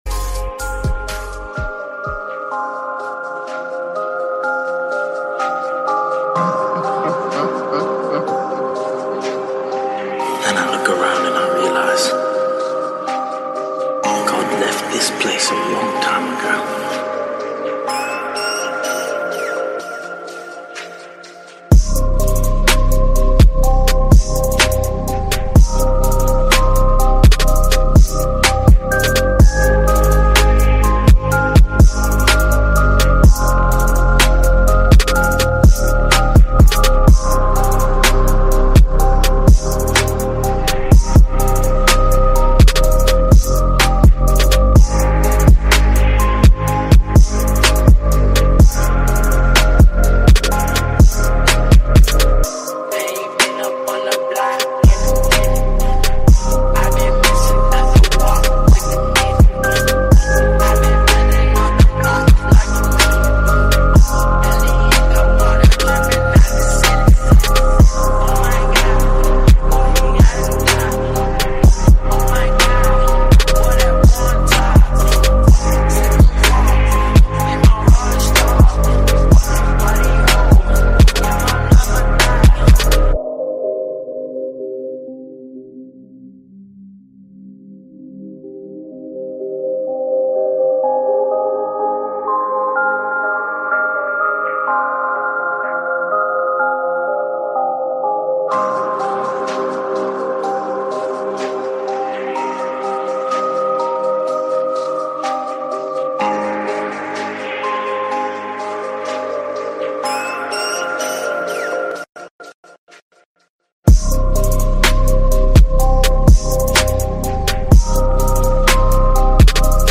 Фонк музыка
фонк в машину